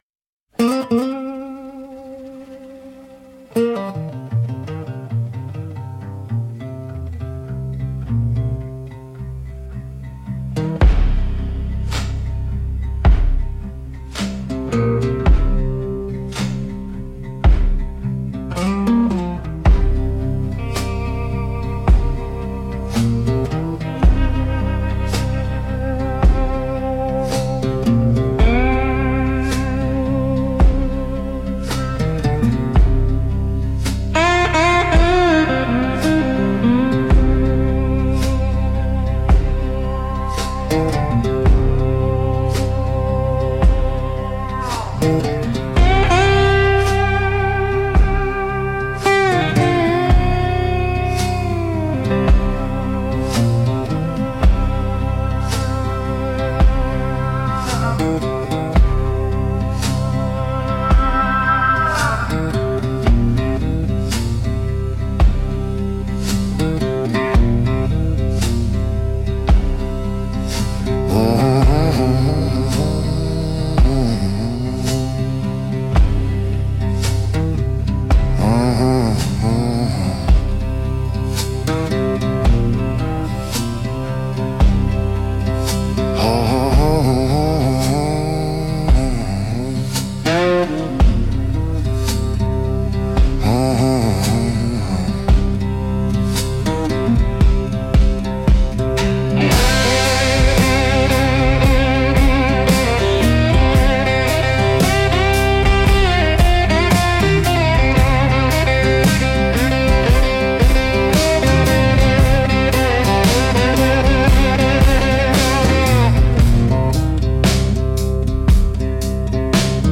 Instrumental - The River’s Gritty Sermon